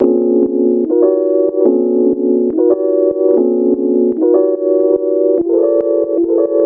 民族爵士乐和声在78罗得中的应用
描述：罗德队参加了7/8的比赛
Tag: 巴尔干 民族 爵士 罗德